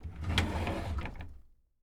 ES_Drawer Open Hotel 4 - SFX Producer.wav